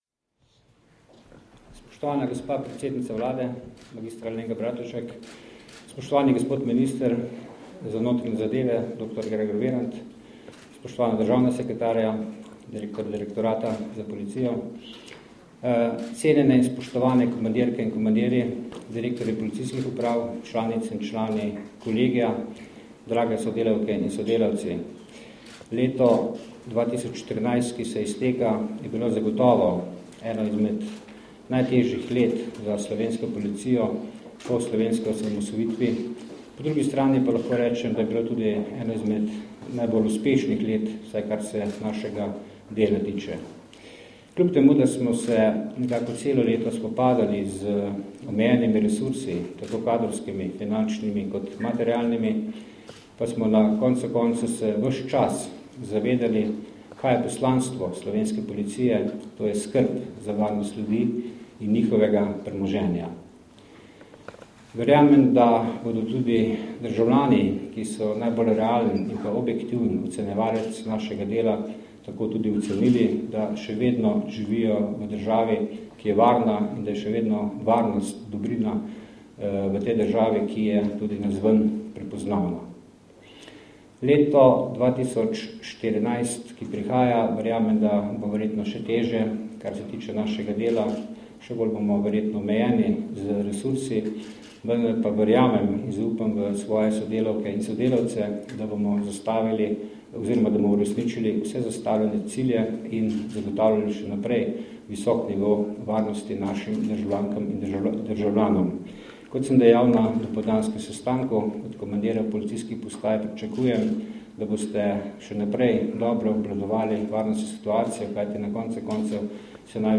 Zvočni posnetek nagovora generalnega direktorja policije (mp3)